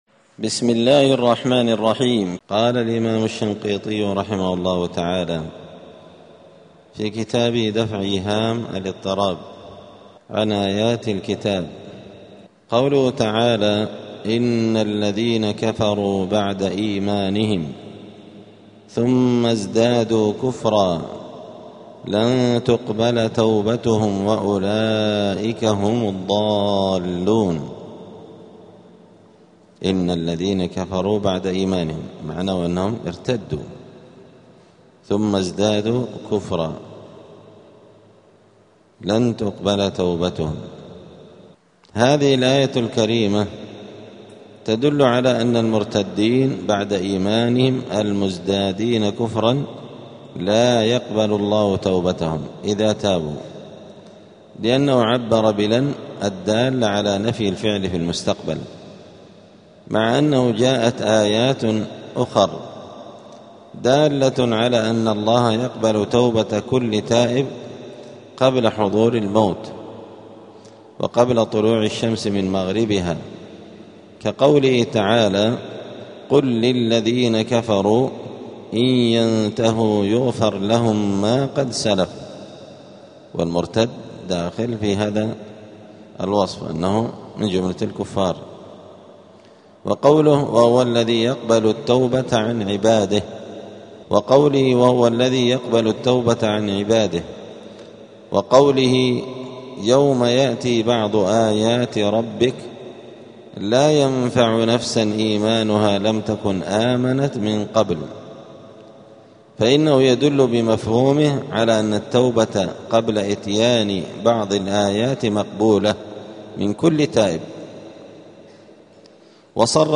*الدرس السابع عشر (17) {سورة آل عمران}.*